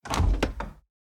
Chest Close 2.ogg